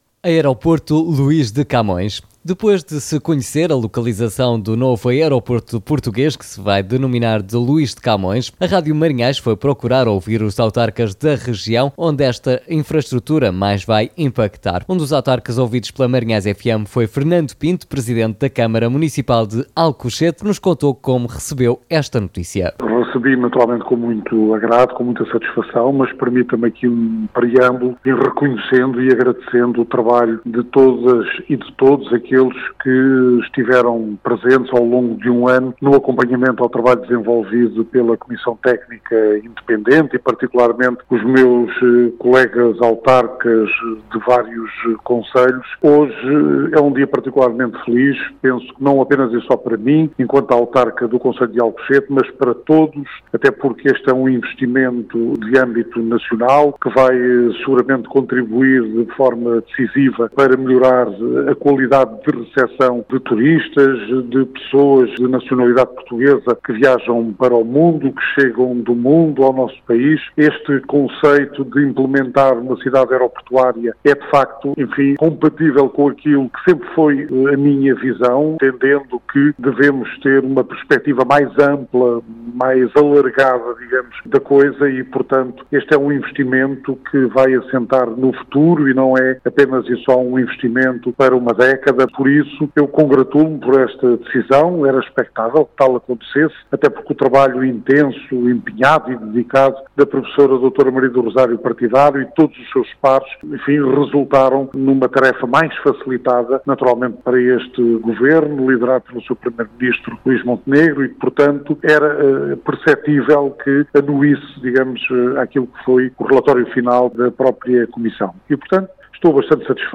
Um desses autarcas foi Fernando Pinto, Presidente da Câmara Municipal de Alcochete.